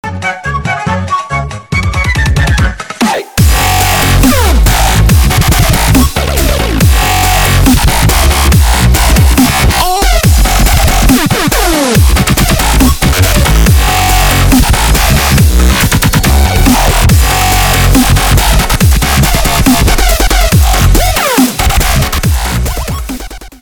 Дабстеп рингтоны